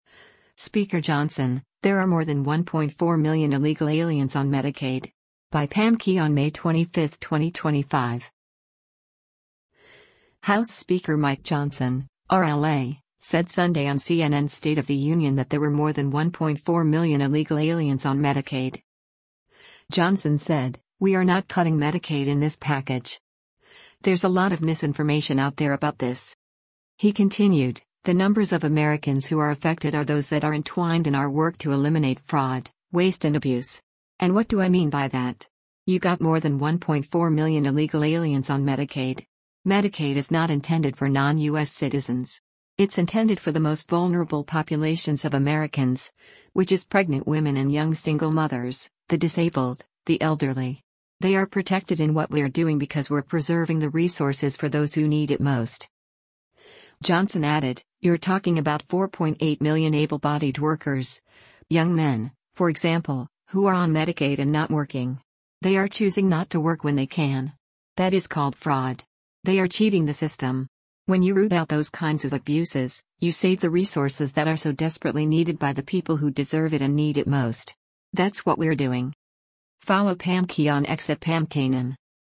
House Speaker Mike Johnson (R-LA) said Sunday on CNN’s “State of the Union” that there were “more than 1.4 million illegal aliens on Medicaid.”